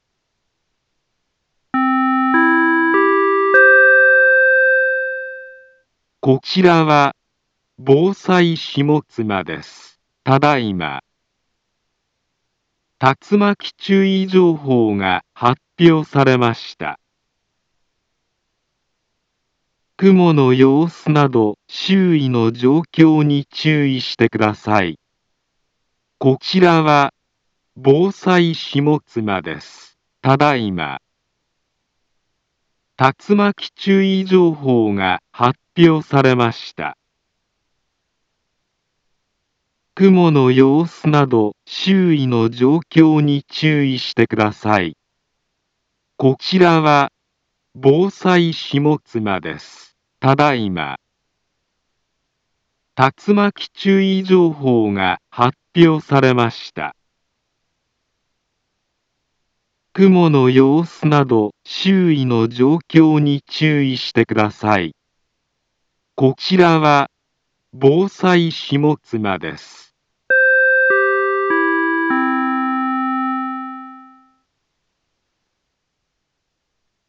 Back Home Ｊアラート情報 音声放送 再生 災害情報 カテゴリ：J-ALERT 登録日時：2022-06-27 19:29:41 インフォメーション：茨城県北部、南部は、竜巻などの激しい突風が発生しやすい気象状況になっています。